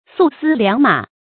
素絲良馬 注音： ㄙㄨˋ ㄙㄧ ㄌㄧㄤˊ ㄇㄚˇ 讀音讀法： 意思解釋： 以之為禮遇賢士之辭。